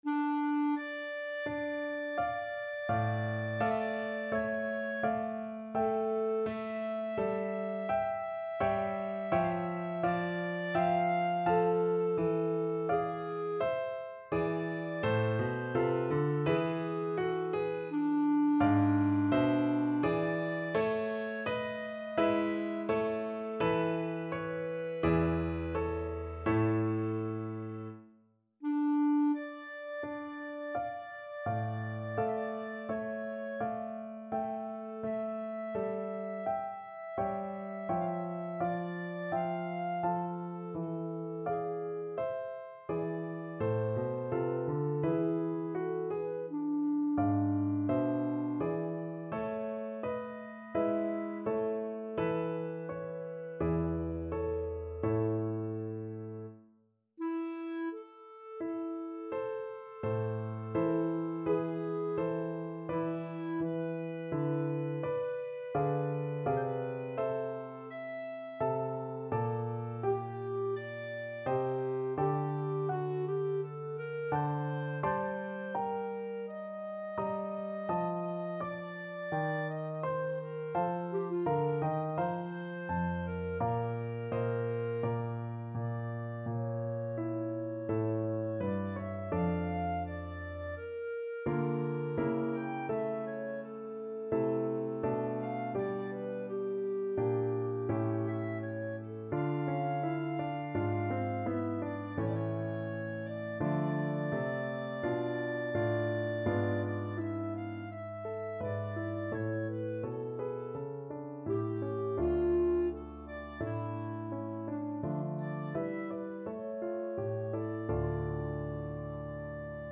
Free Sheet music for Clarinet
Clarinet
4/4 (View more 4/4 Music)
Largo =42
Classical (View more Classical Clarinet Music)